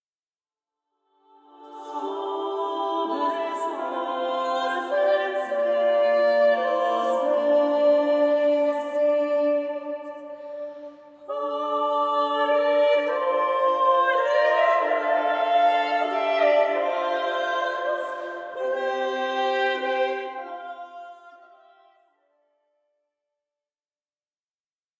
célèbre ensemble vocal
les voix des chanteuses